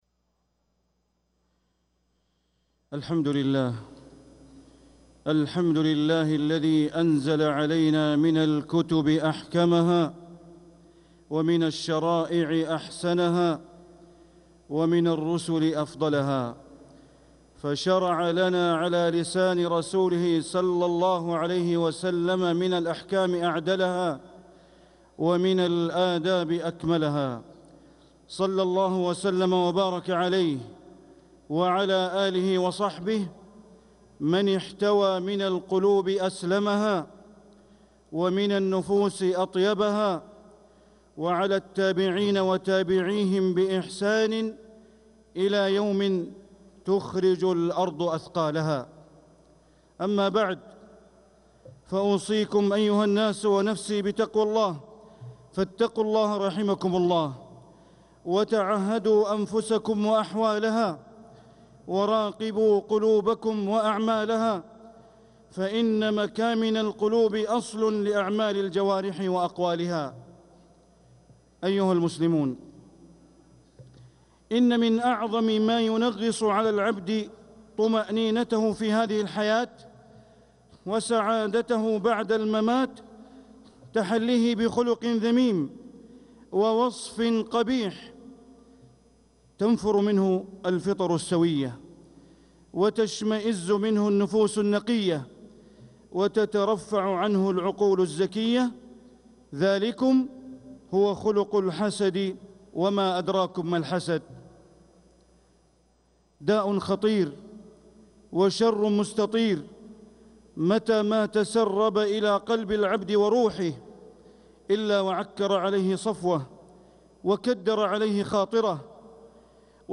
خطبة الجمعة 13 ربيع الأول 1447هـ ( بعنوان الحسد ) > خطب الشيخ بندر بليلة من الحرم المكي > المزيد - تلاوات بندر بليلة